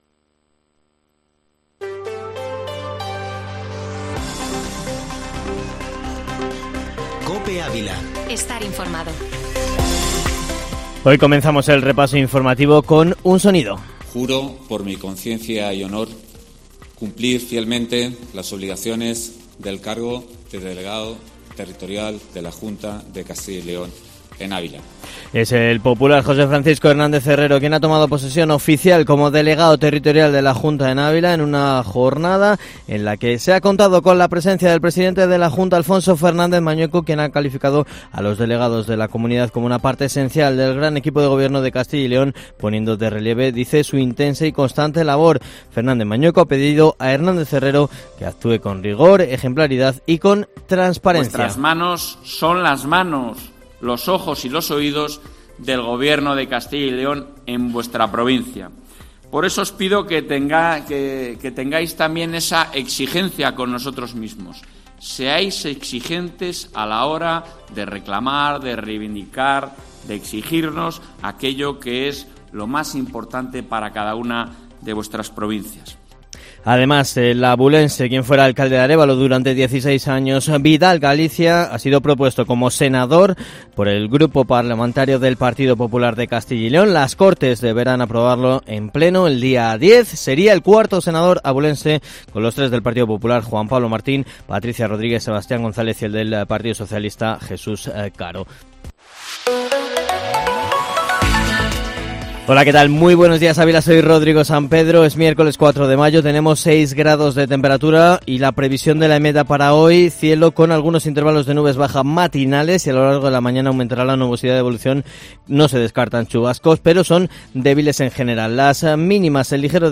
Informativo Matinal Herrera en COPE Ávila -4-mayo